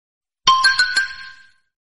Dzwonek - Cymbałki